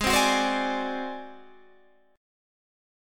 Ab7b5 Chord
Listen to Ab7b5 strummed